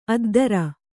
♪ addara